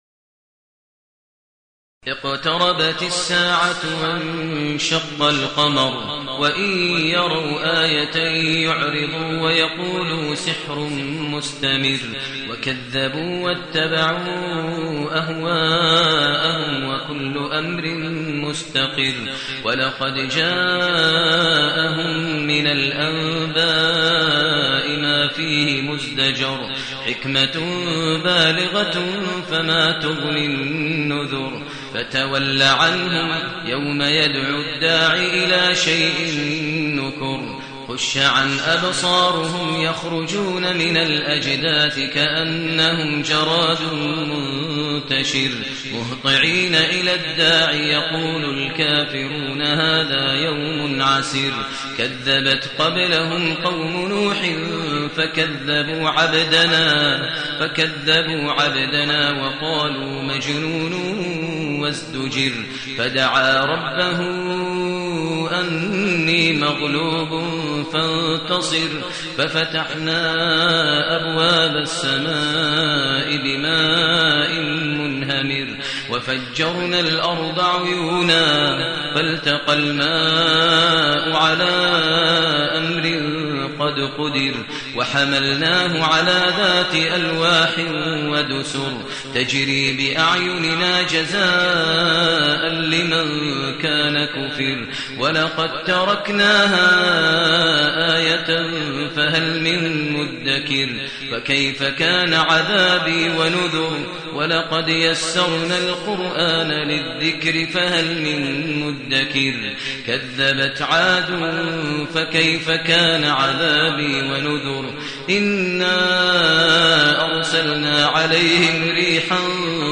المكان: المسجد الحرام الشيخ: فضيلة الشيخ ماهر المعيقلي فضيلة الشيخ ماهر المعيقلي القمر